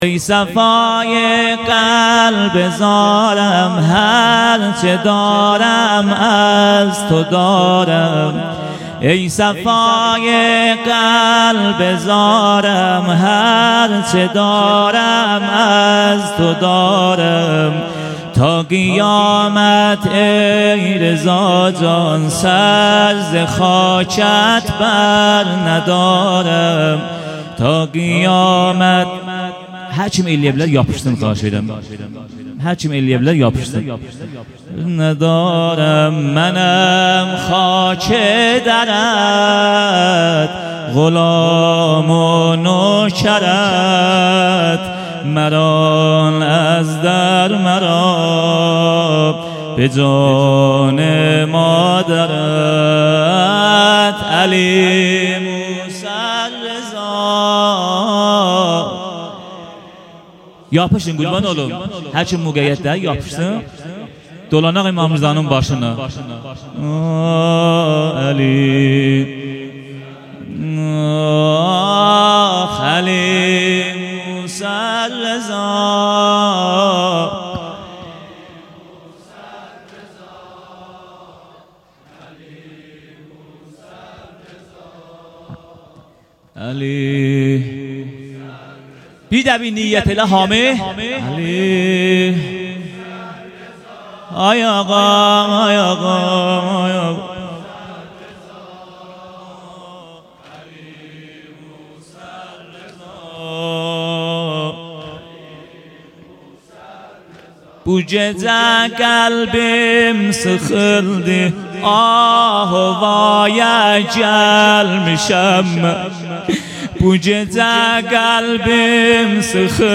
هیات هفتگی
بخش اول سینه زنی